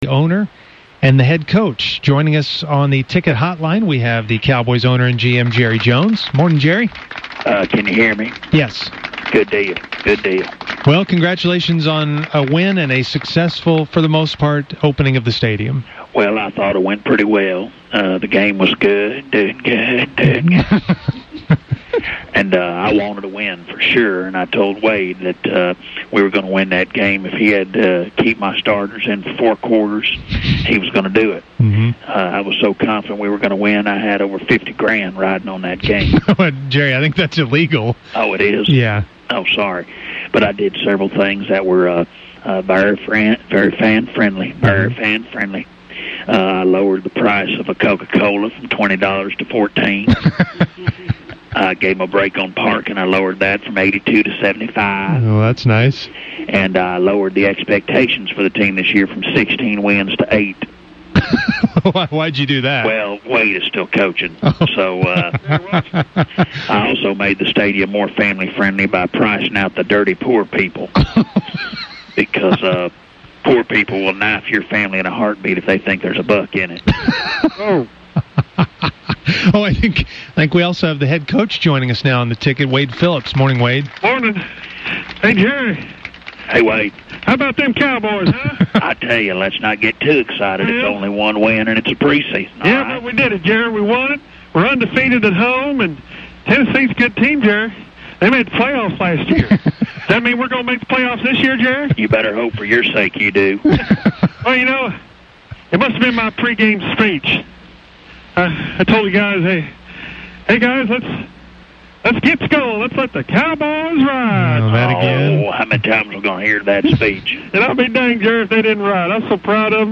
The traditional Monday morning conversation with the fake Cowboys owner and coach after a weekend game, proved to be entertaining.